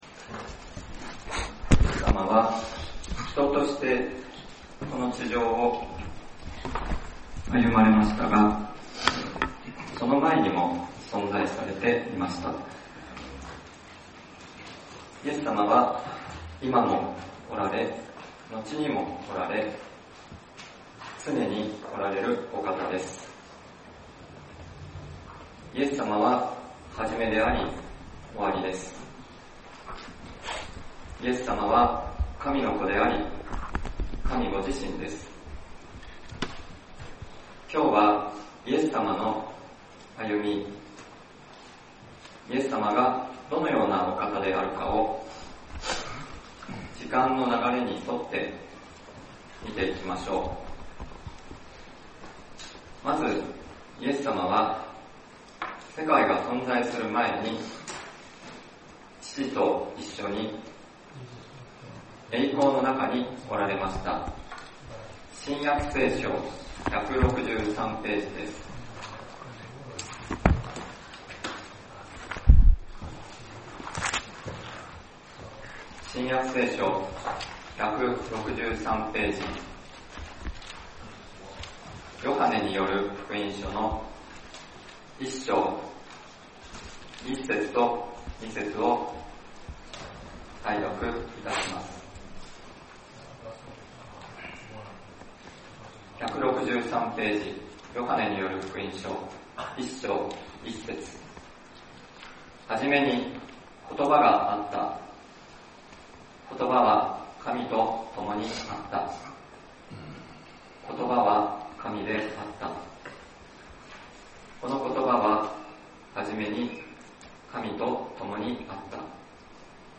先週，東京集会で行わ れた礼拝で録音された建徳です。